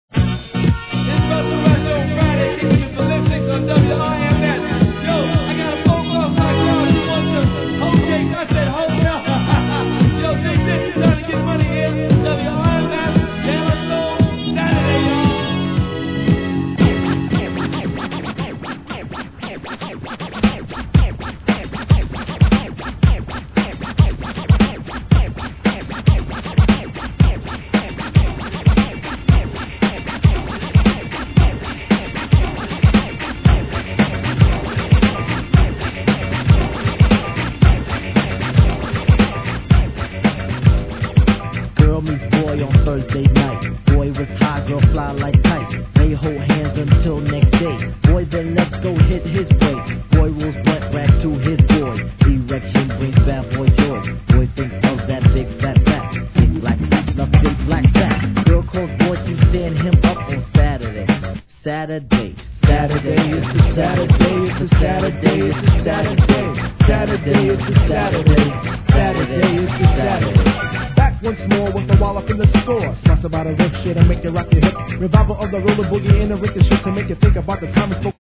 HIP HOP/R&B
このイントロのこすり具合も最高にイイ!!